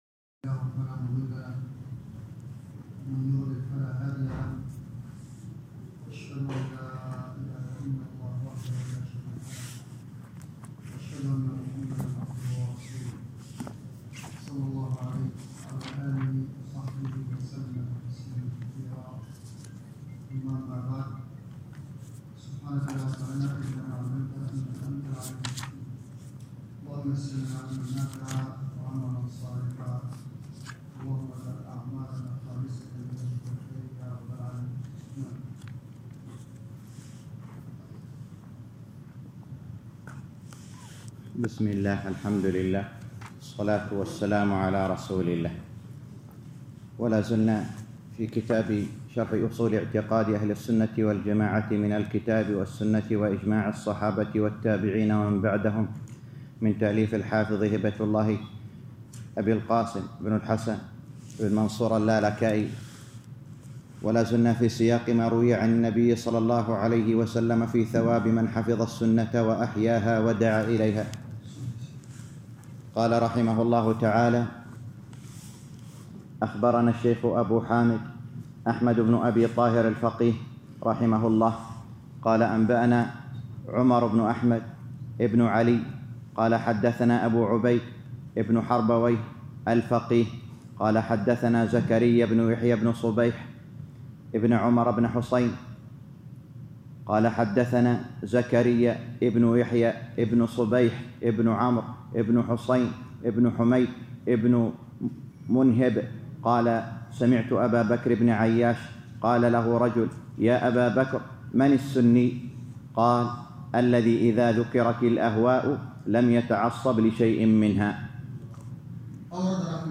الدرس الرابع عشر - شرح أصول اعتقاد اهل السنة والجماعة الامام الحافظ اللالكائي _ 14